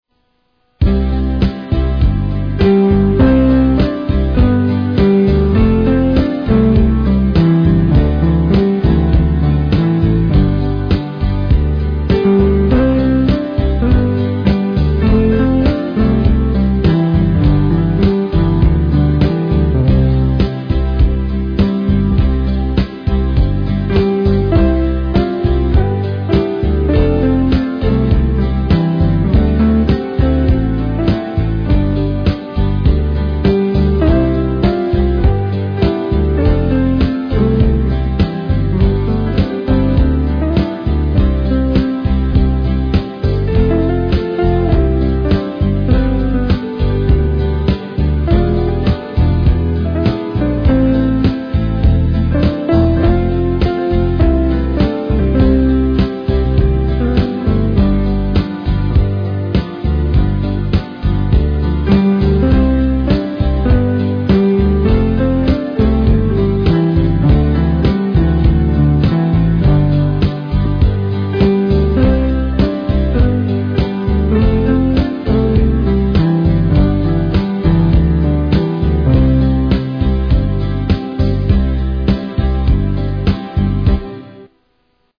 Country mp3